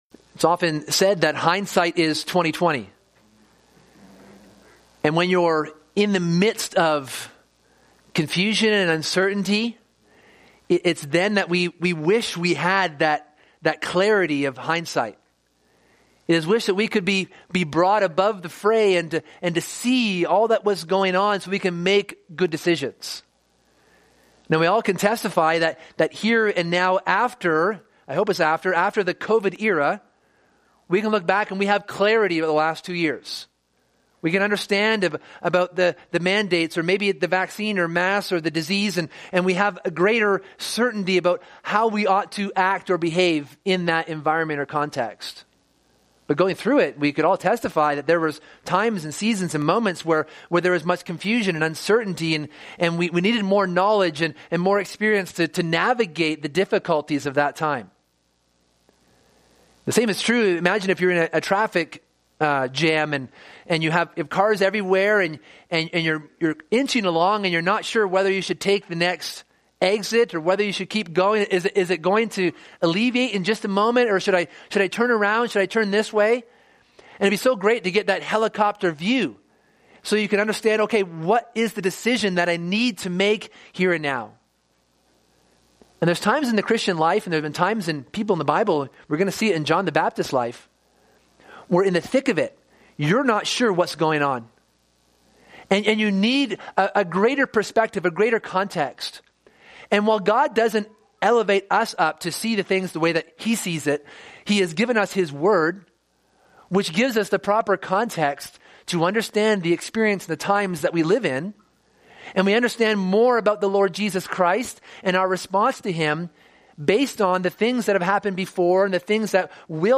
This sermon considers how John the Baptist was stumbling over Jesus and was wondering whether he was the Christ or should they look for another.